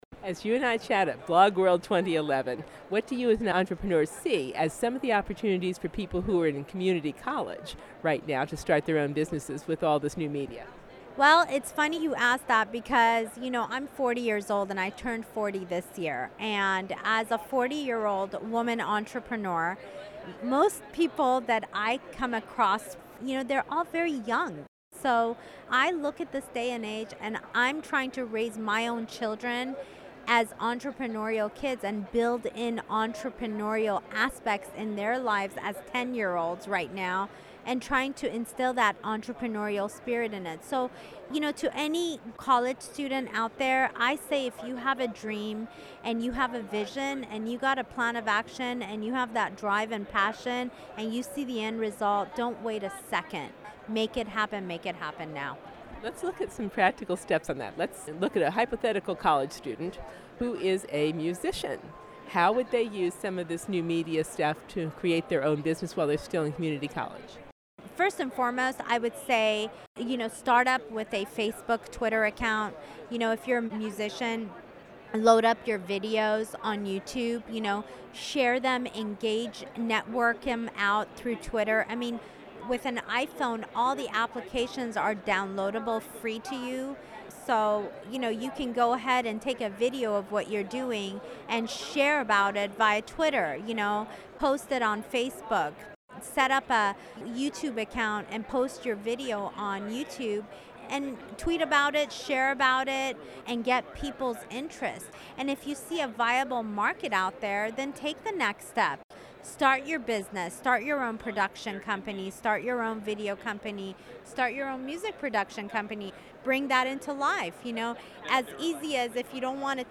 BlogWorld 2011